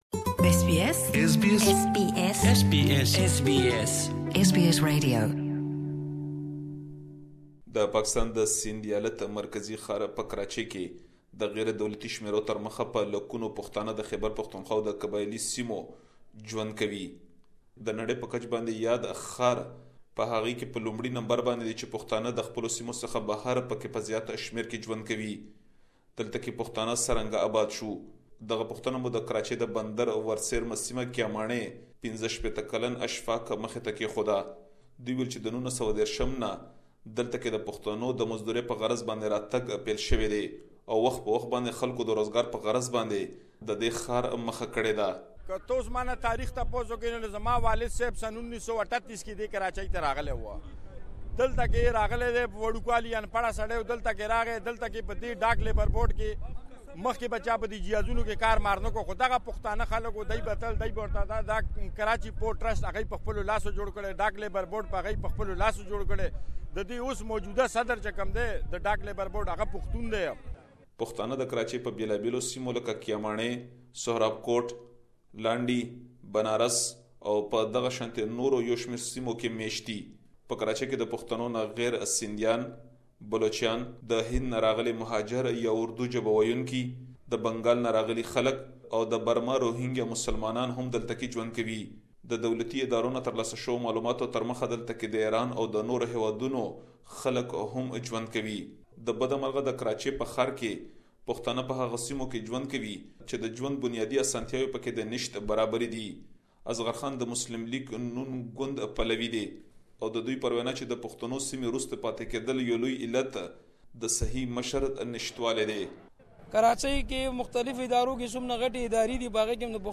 Pashtun residing in Karachi spoke to SBS reporter